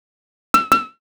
fire-1.wav